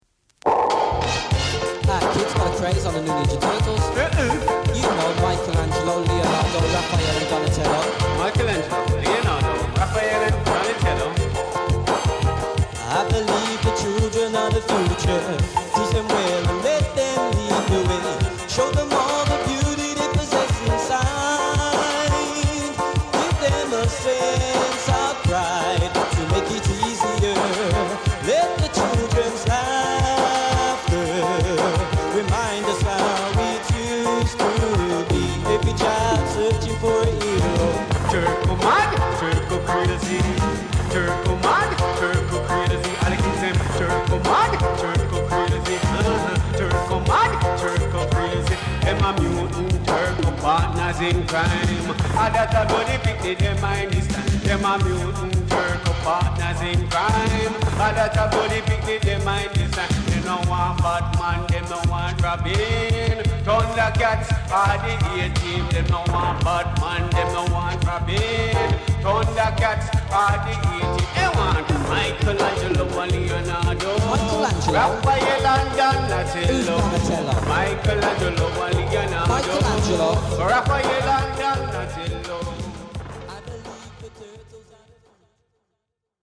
正体不明のマイナー・ダンスホール12インチ。